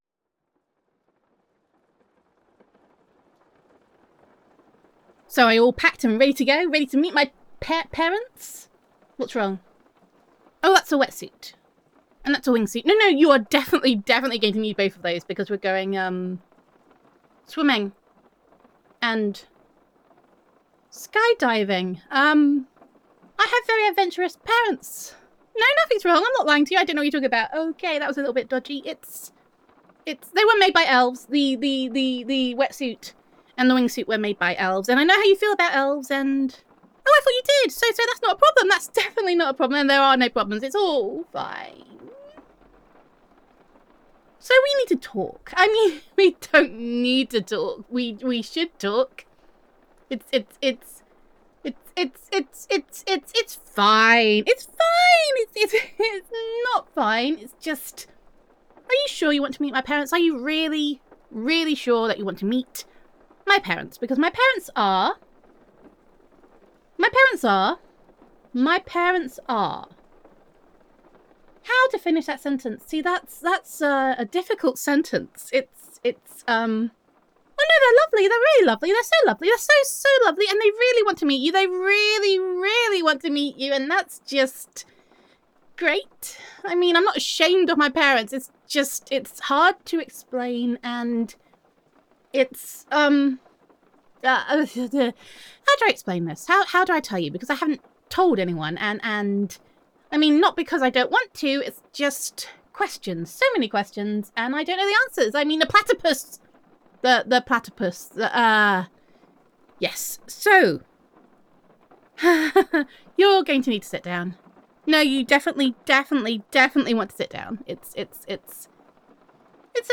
[F4A] The Platypus [Girlfriend Roleplay][Awkward AF][Meeting the Parents][Reverse Comfort][Don’t Think About It Too Hard][You Make Me Feel Special][Adoration][A Little Bit Magic][Gender Neutral][Your Girlfriend Wants You to Meet Her Parents but There Is Something You Need to Know First]